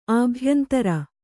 ♪ ābhyantara